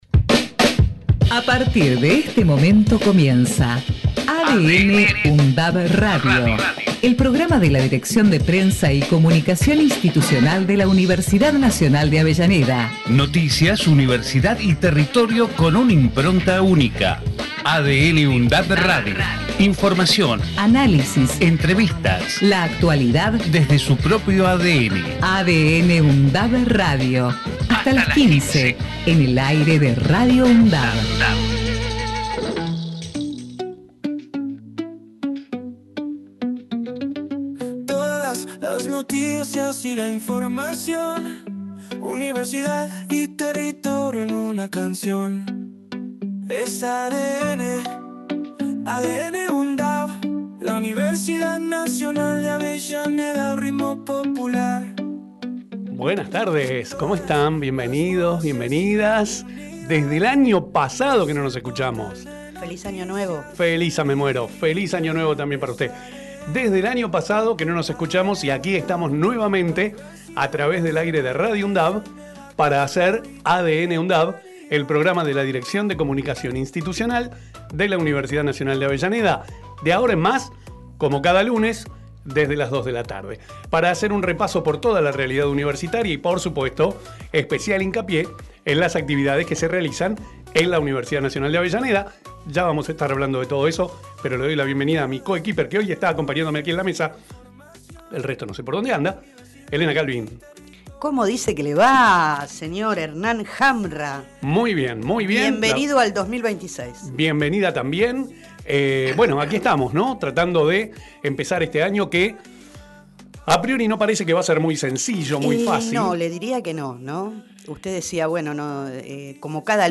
ADN|UNDAV – Radio tiene la vocación de ser un aporte en tal sentido, a través de secciones como “Temas de la Uni”, “Entrevistas”, “Lo que pasa” y “En comunidad”.